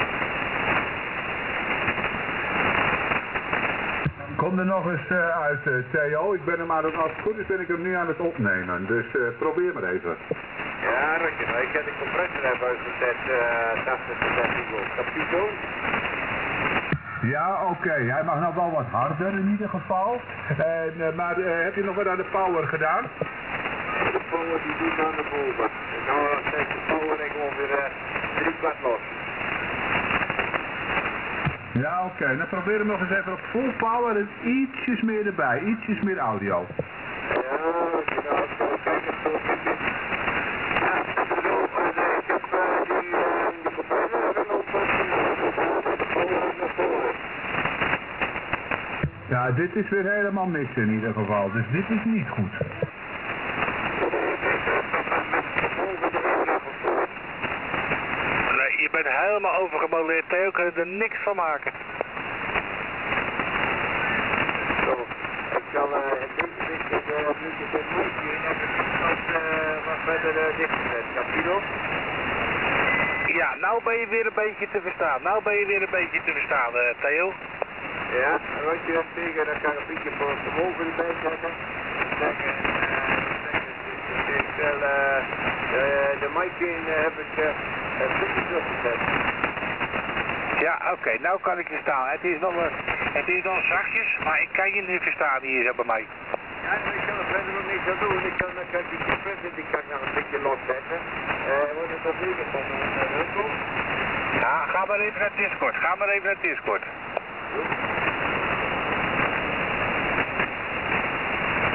HAM Radio Station from Texel Island